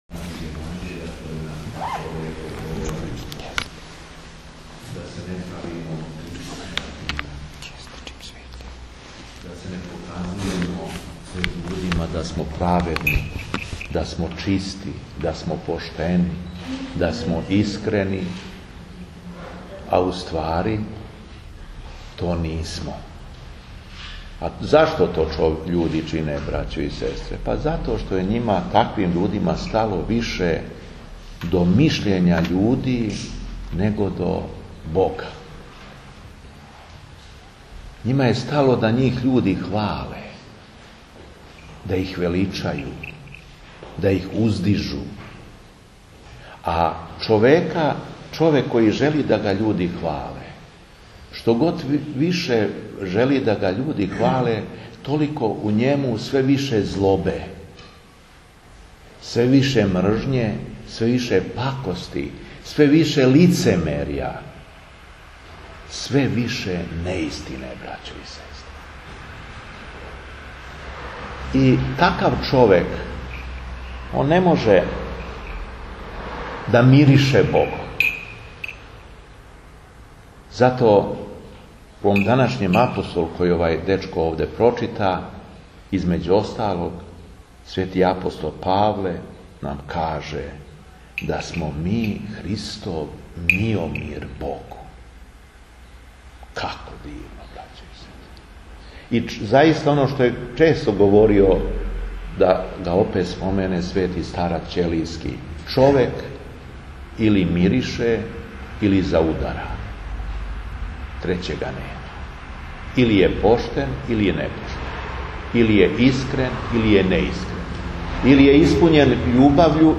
СВЕТА ЛИТУРГИЈА У СУШИЦИ - Епархија Шумадијска
Беседа епископа шумадијског Г. Јована